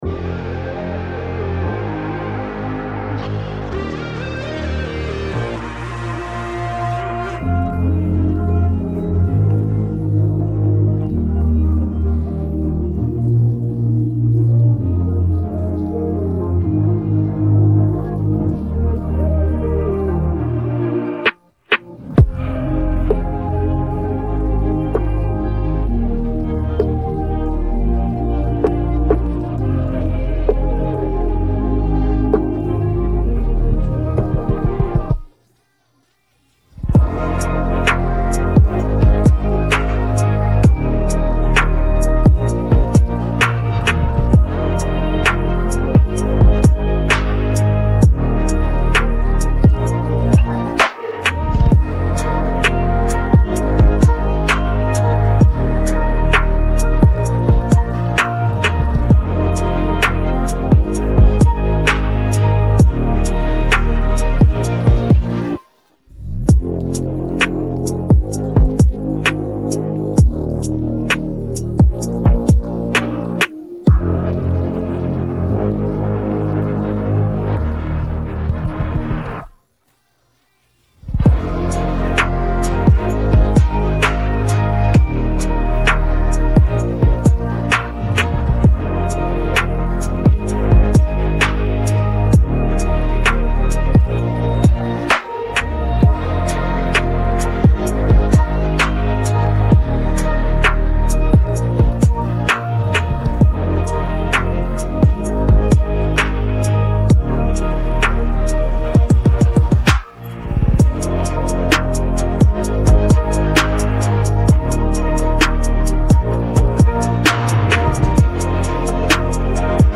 Muzik latar